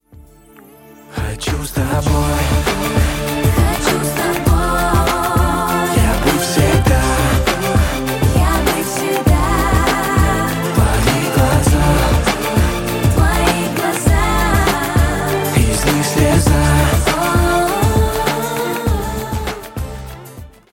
• Качество: 128, Stereo
поп
дуэт